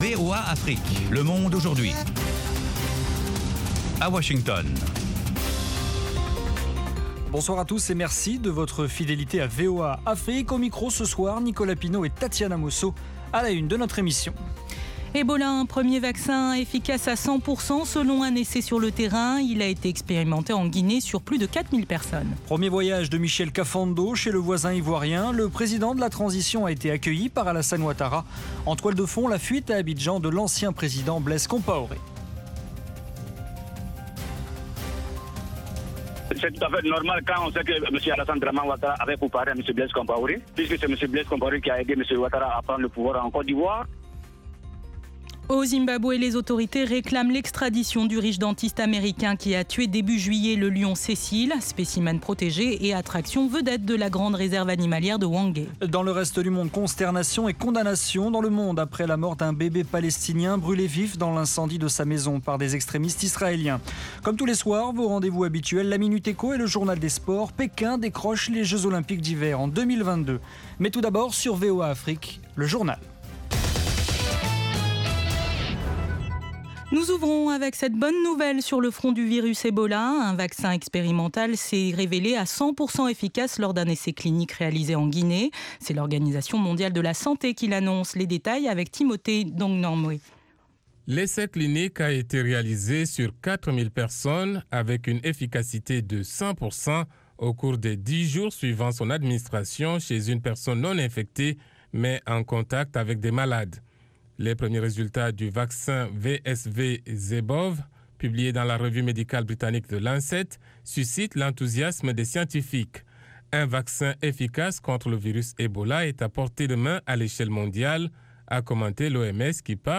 Interviews, reportages de nos envoyés spéciaux et de nos correspondants, dossiers, débats avec les principaux acteurs de la vie politique et de la société civile. Aujourd'hui l'Afrique Centrale vous offre du lundi au vendredi une synthèse des principaux développementsdans la région.